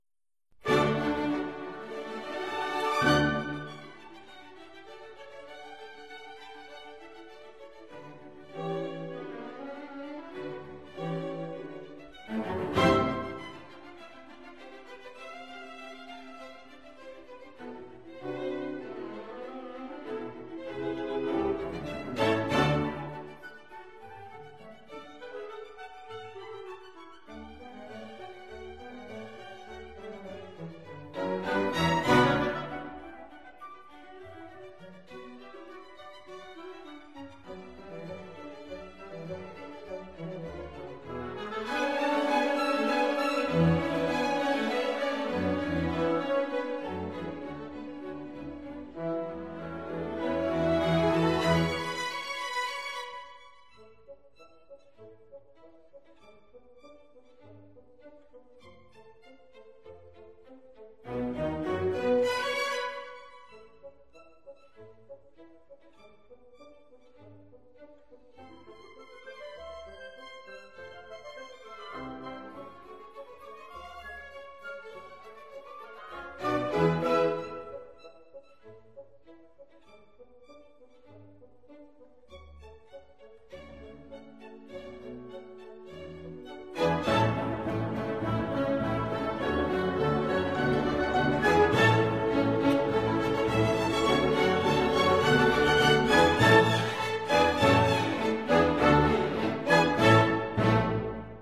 1984 Half note = 99